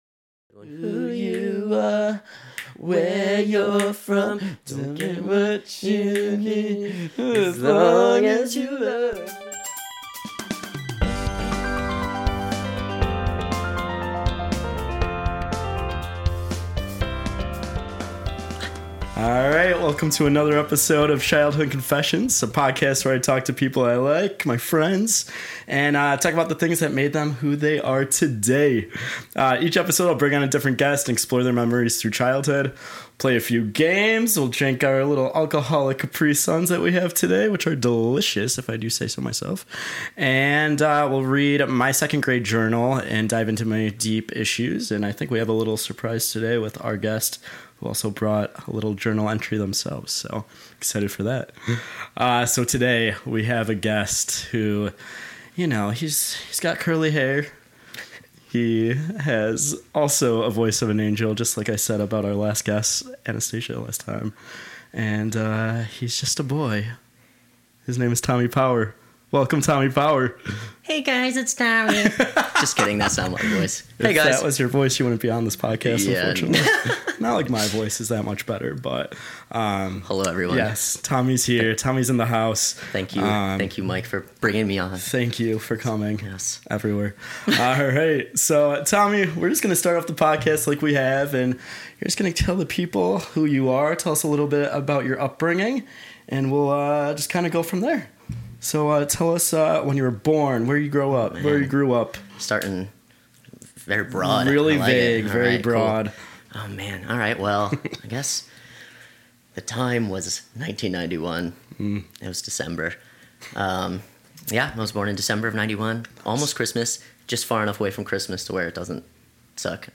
We then switch gears and dive into a fun round of 90s and 2000s trivia, testing each other's knowledge on the pop culture that defined our youth.
It's an episode packed with laughter, nostalgia, and a whole lot of fun.